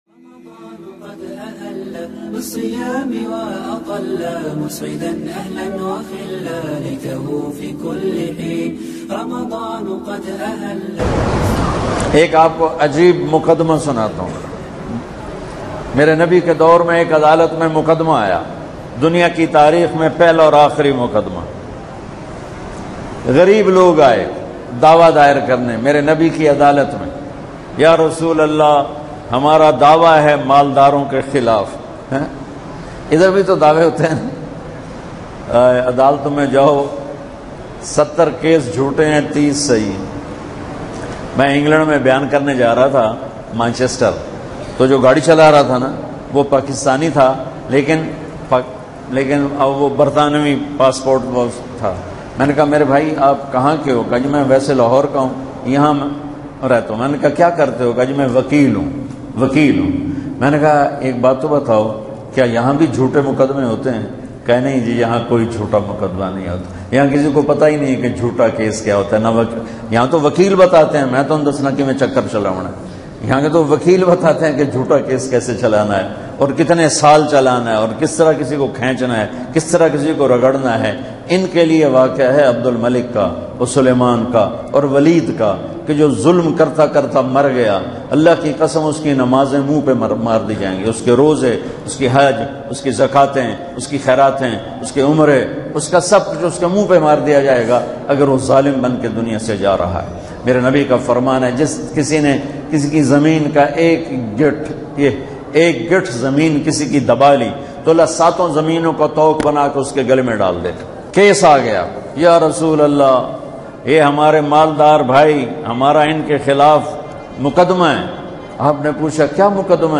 Maulana Tariq Jameel Bayan Aik Ajeeb Muqadama mp3